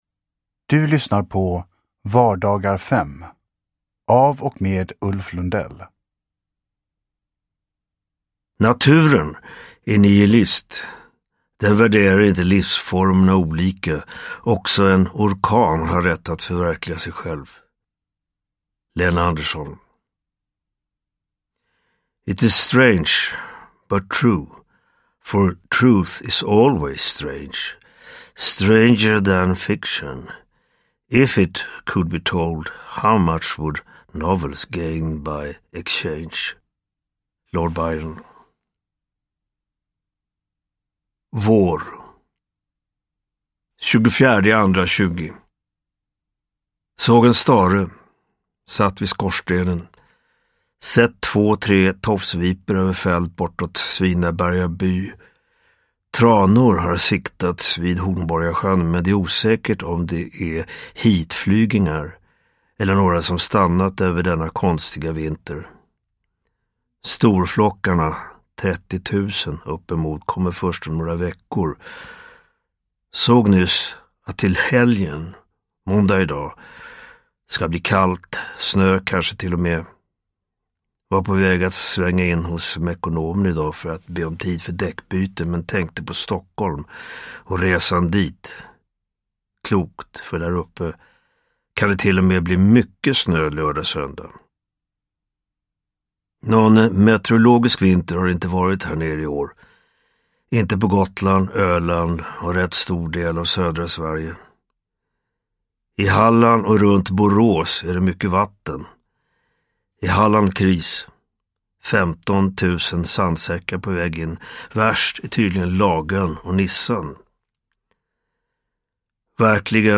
Vardagar 5 – Ljudbok – Laddas ner
Uppläsare: Ulf Lundell